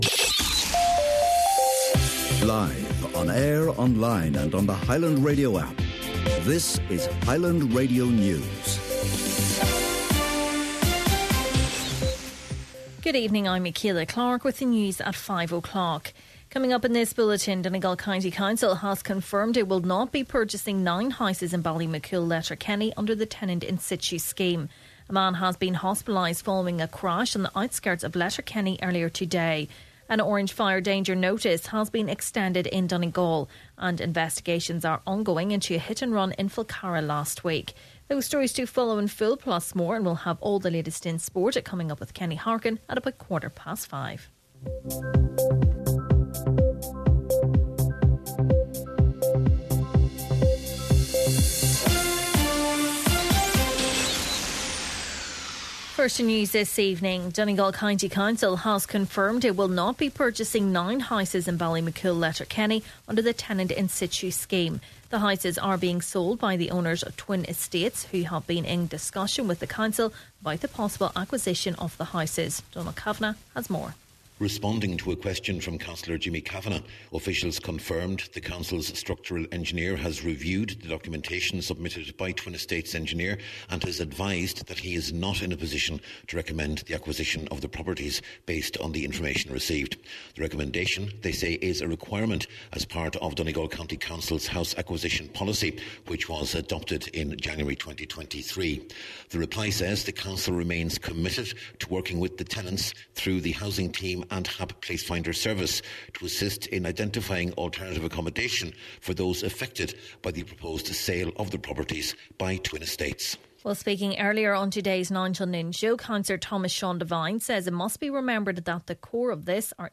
Main Evening News, Sport and Obituaries – Tuesday, April 8th